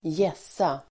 Ladda ner uttalet
Uttal: [²j'es:a]